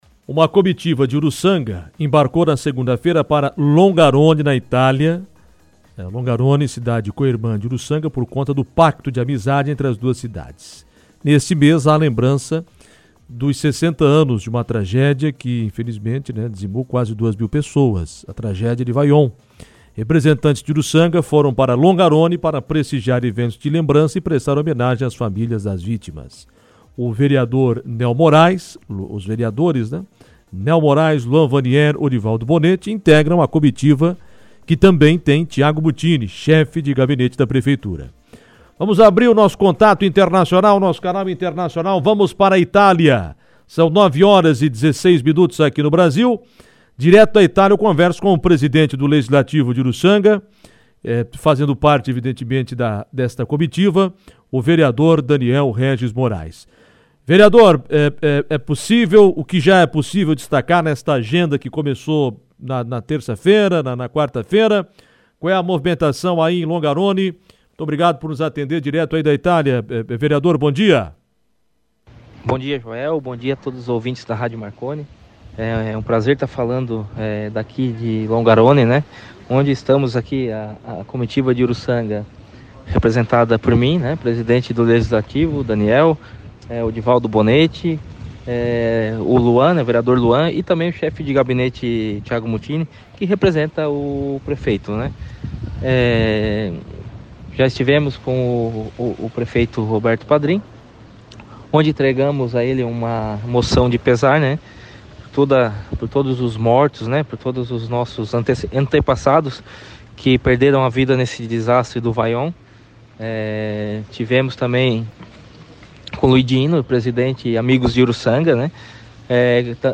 Assunto foi destaque em entrevista no Comando Marconi.